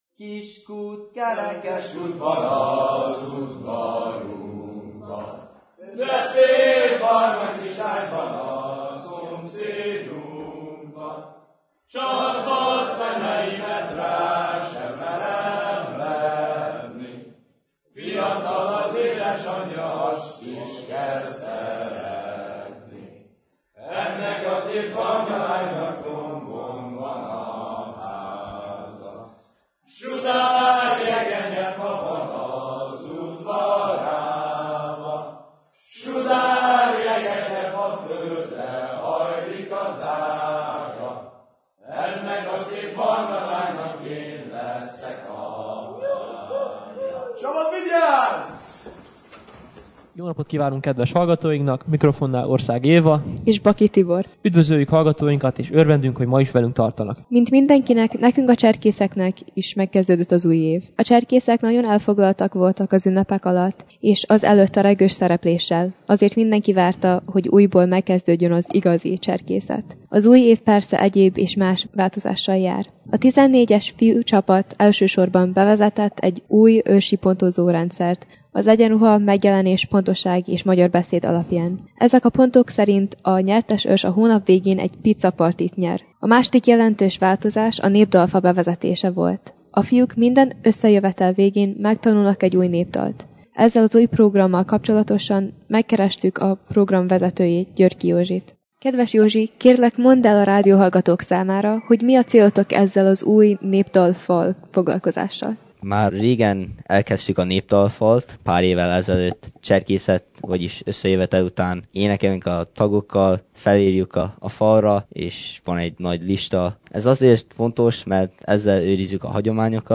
A 2014-es MHBK bál fiataljai nyilatkoznak a bálról, A 14-es fiú cserkészcsapat tagjai népdalokat énekelnek (sokat és ügyesen!)